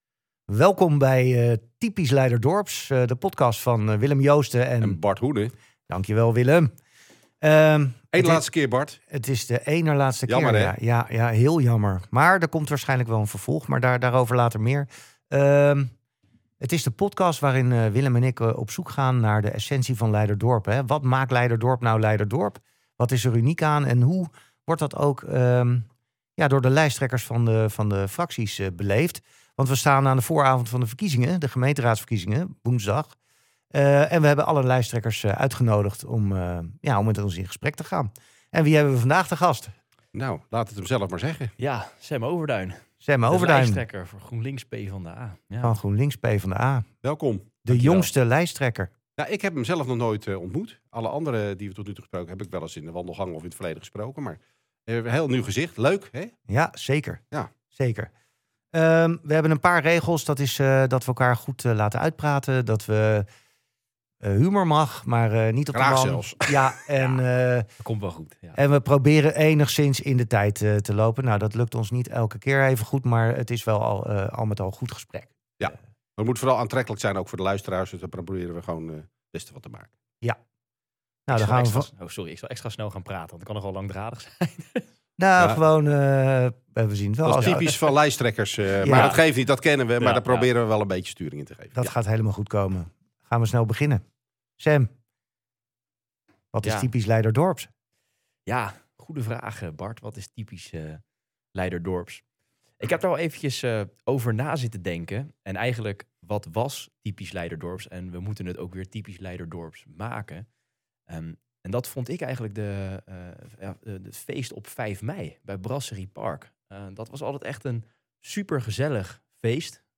Typisch Leiderdorp is een podcast van twee opgewekte, ondernemende Leiderdorpers met hart voor het dorp.
eerlijk, scherp en vaak met een knipoog over wat er speelt in Leiderdorp.